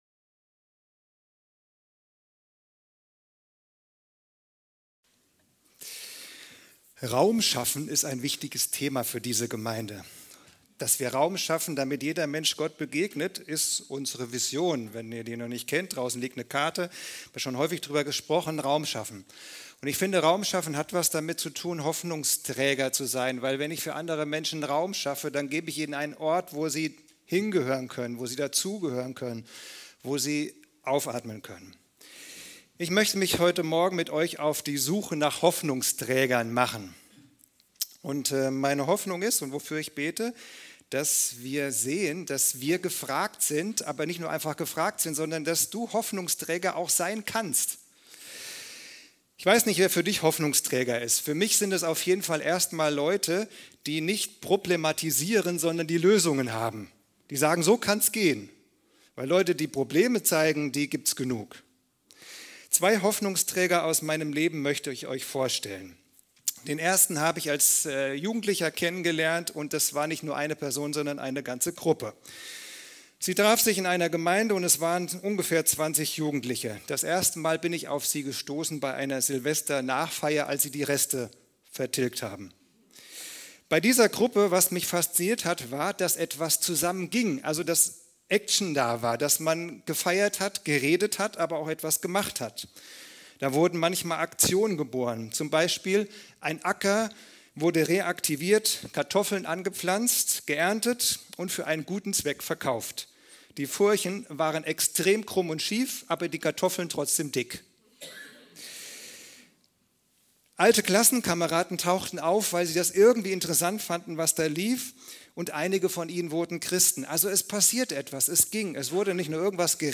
(Die Aufnahme stammt vom Gottesdienst in der FeG Pohlheim, aber der Inhalt ist praktisch identisch.